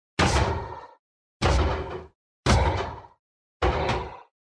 На этой странице собраны разнообразные звуки ударов и урона: резкие атаки, тяжёлые попадания, критические удары.
Звук удара железного Голема